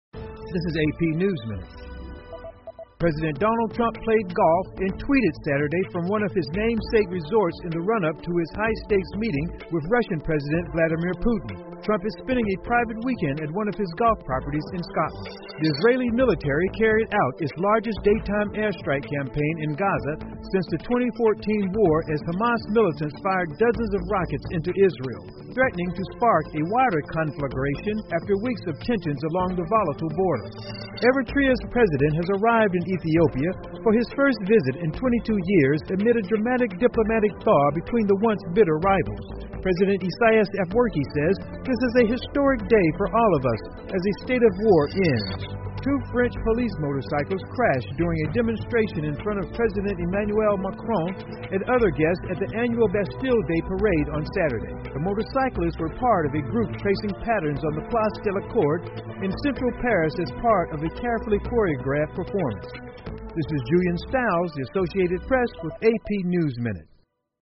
美联社新闻一分钟 AP 法国阅兵两摩托车相撞 听力文件下载—在线英语听力室